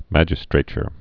(măjĭ-strāchər, -strə-chr)